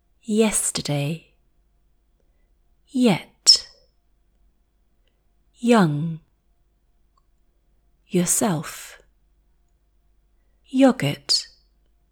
While a rolled /r/ is made by tapping your upper palate with the tip of your tongue, RP speakers pronounce /r/ by raising the tongue and adding muscle tension without the tongue tip touching anything.